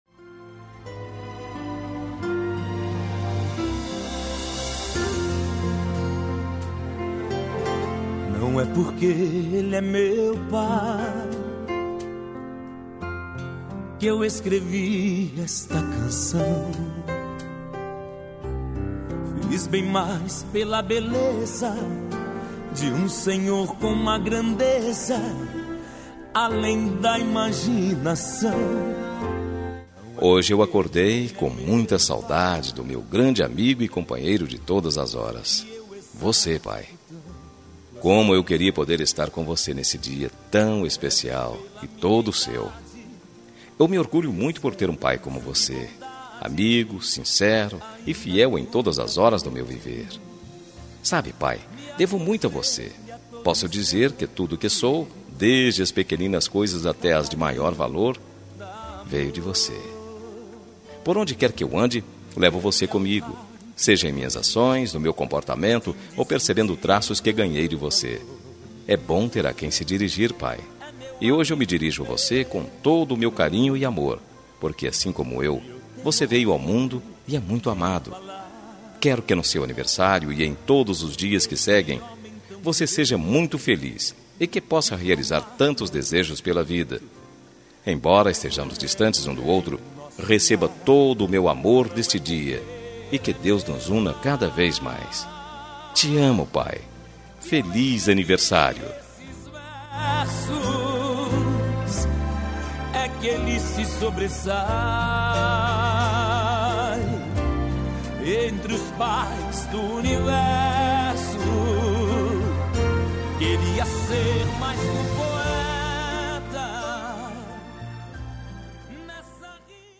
Telemensagem de Aniversário de Pai – Voz Masculina – Cód: 1515 Distante Linda